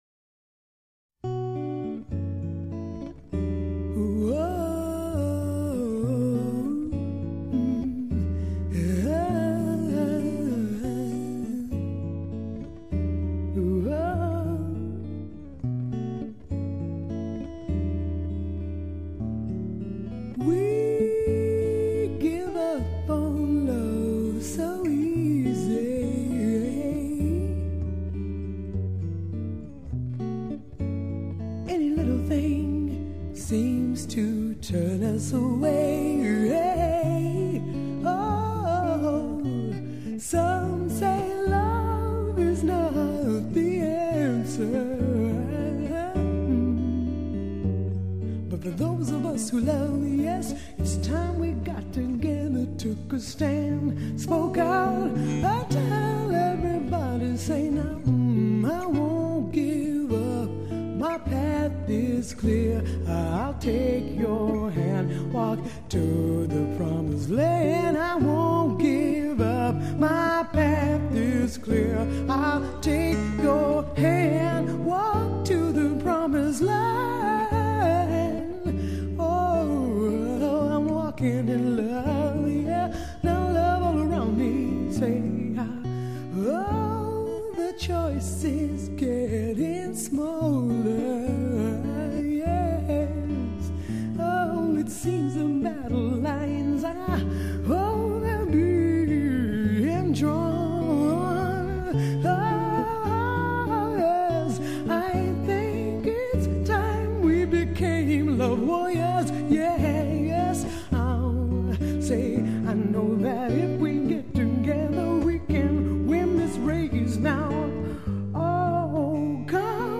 當代靈魂樂派吟唱‧分分秒秒融化你的靈魂
單單一把吉他配上女聲吟詠，是恰到好處的淡，又是一嚐便醉的醺，二十餘年來風靡至今，仍是餘韻盪漾。